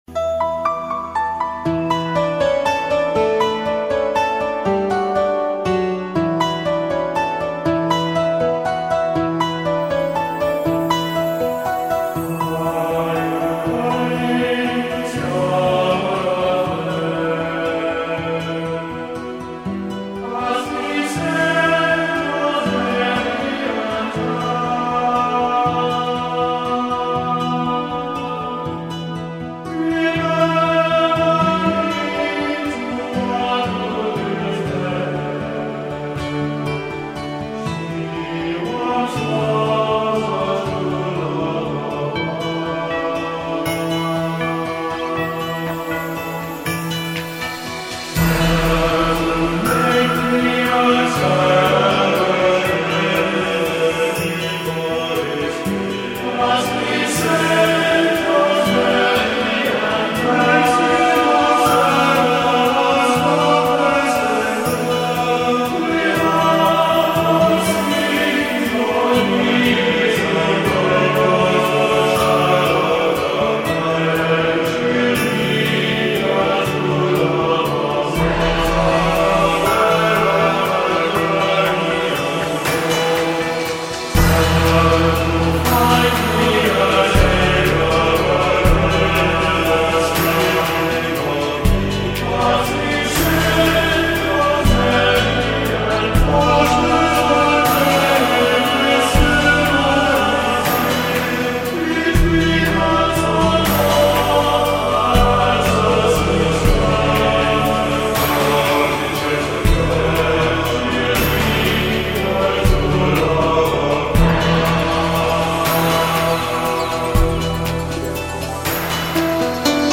Gregorian-Scarborough_Fair.mp3